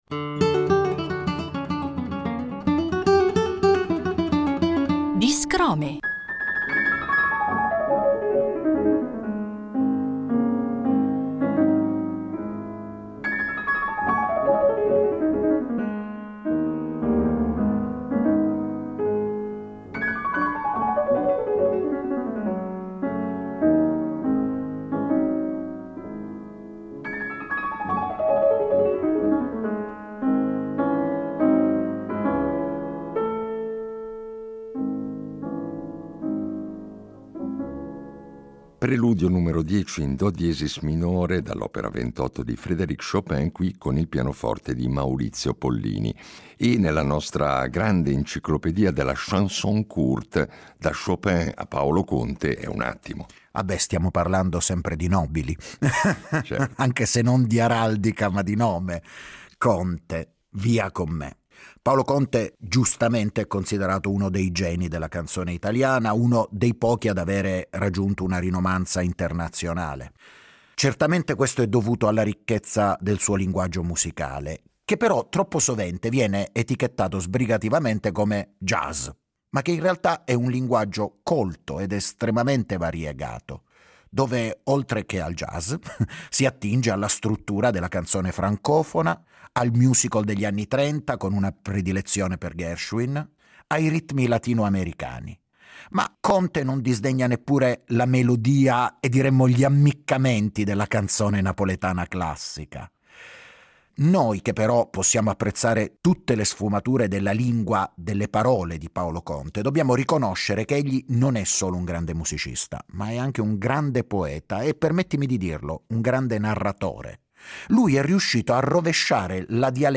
Vi facciamo ascoltare queste canzoni, in versione discografica o live, raccontandovi qualcosa dei loro autori e del contesto in cui nacquero… poesia, musica e storie in cinque minuti: un buon affare!